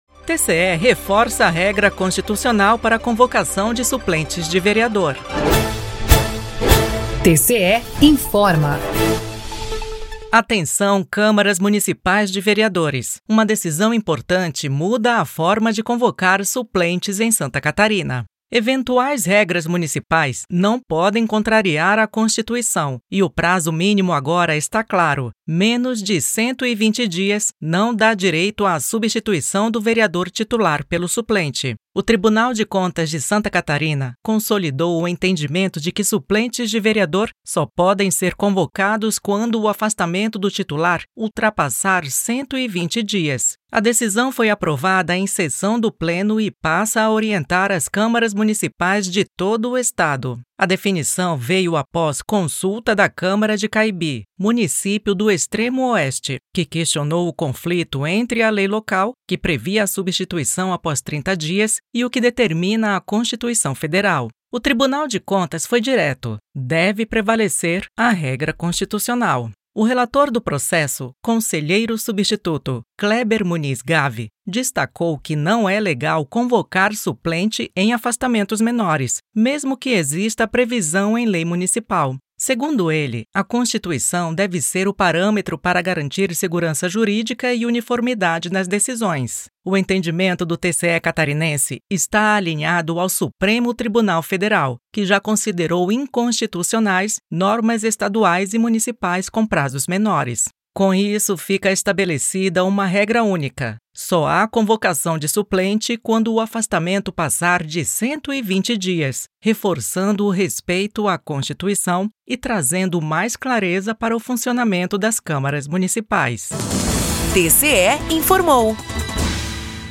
VINHETA TCE INFORMA